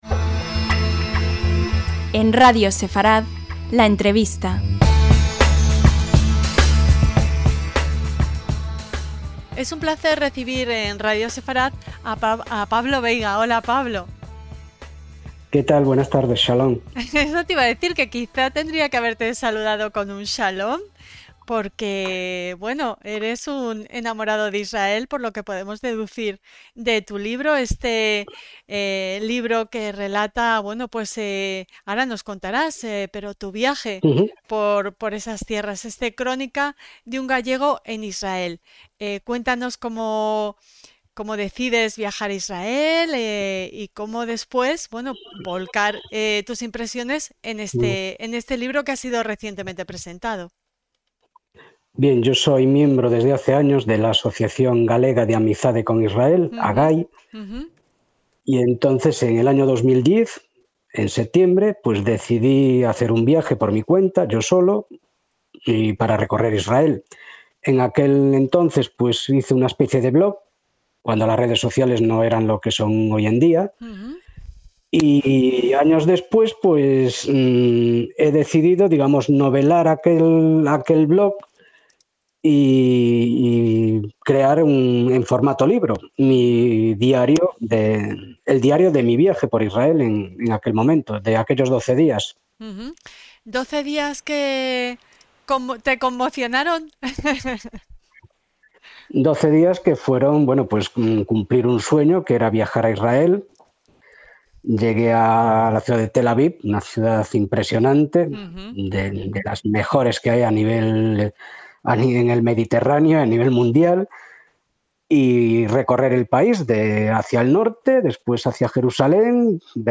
LA ENTREVISTA